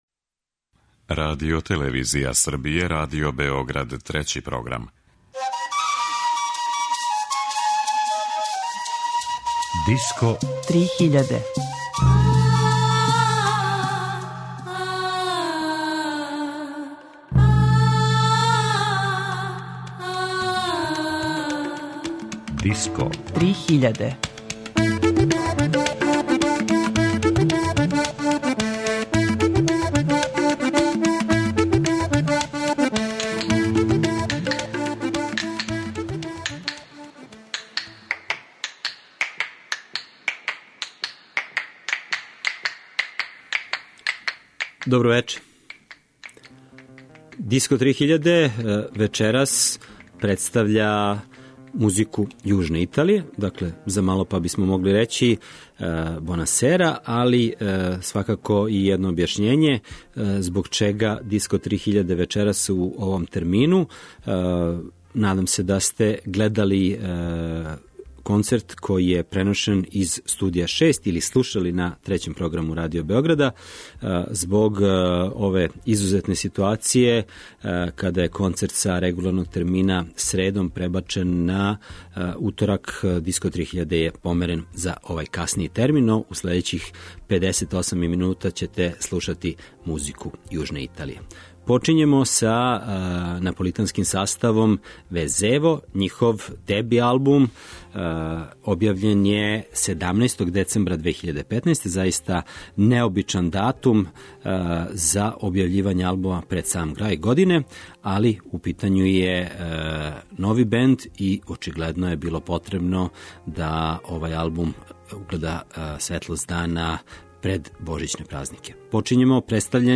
Музика Италије